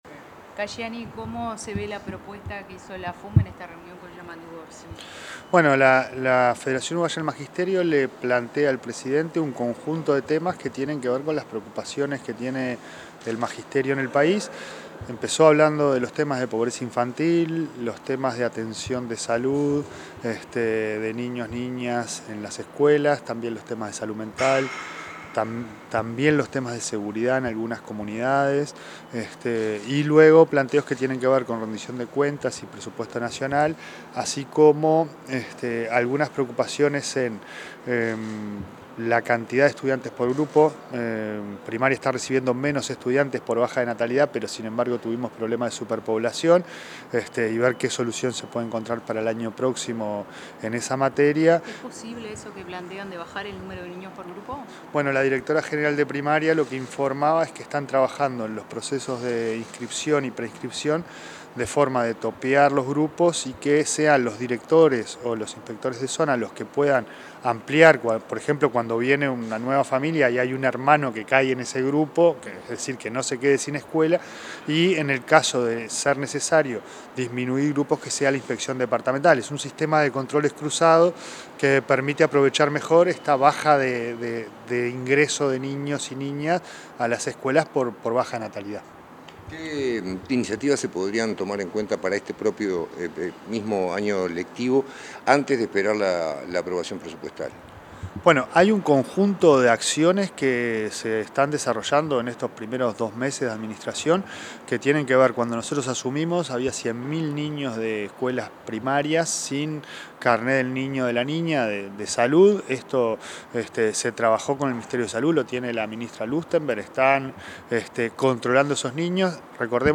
Declaraciones del presidente de ANEP, Pablo Caggiani
El presidente de la Administración Nacional de Educación Pública (ANEP), Pablo Caggiani, dialogó con la prensa tras la reunión mantenida con el